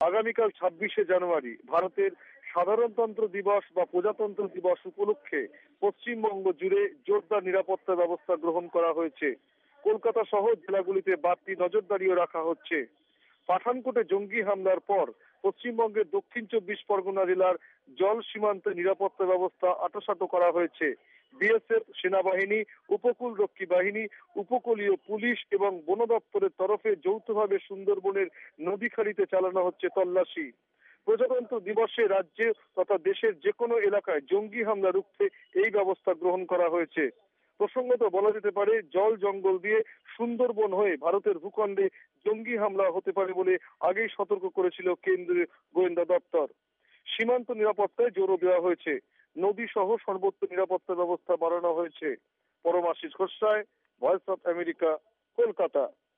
আগামি কাল মঙ্গলবার ২৬ জানুয়ারী ভারতের প্রজাতন্ত্র দিবস বা সাধারন তন্ত্র দিবসের আগে পশ্চিম বঙ্গ জুড়ে জোরদার সূরক্ষা ব্যবস্থা গ্রহন করা হয়েছে- জানাচ্ছেন কলকাতা থেকে